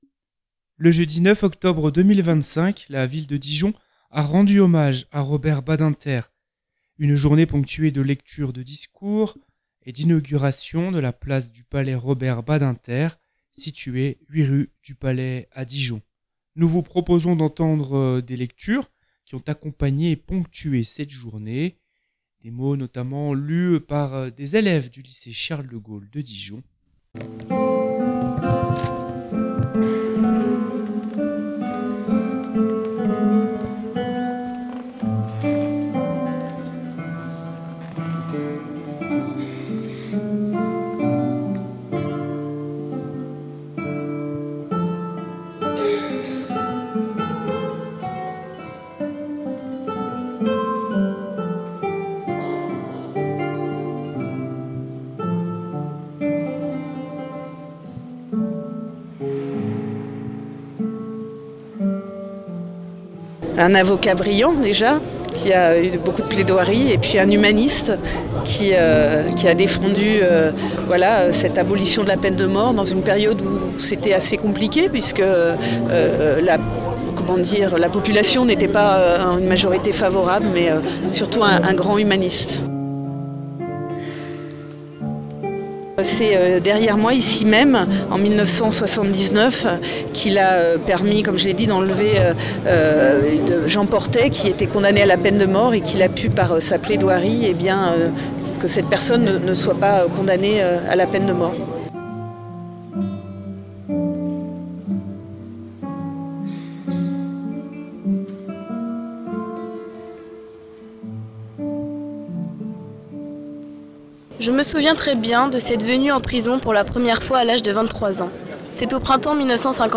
La Ville en saluant l'homme, le résistant, l'avocat, le professeur et le ministre a rappelé ses liens avec Dijon où il est intervenu comme avocat au Palais et comme professeur à l'Université. Reportage